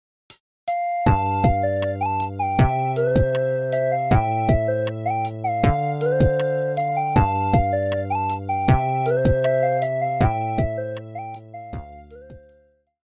Nokia полифония. Зарубежные